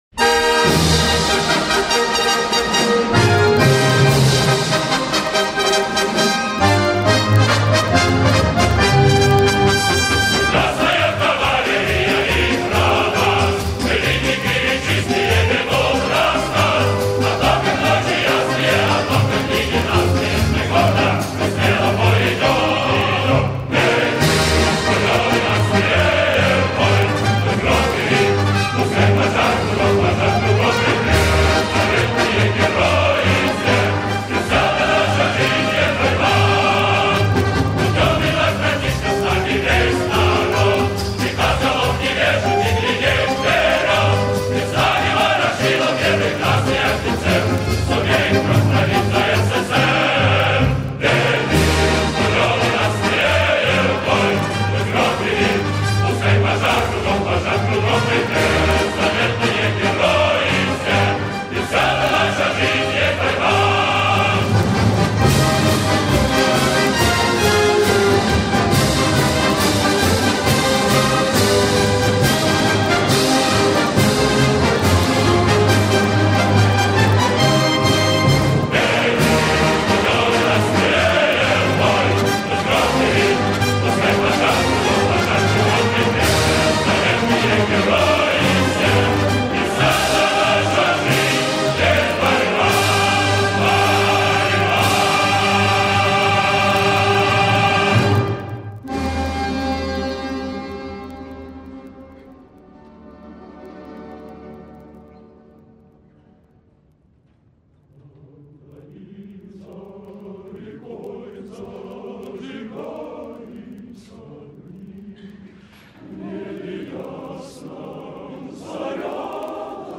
Описание: Три песни, довольно приличное исполнение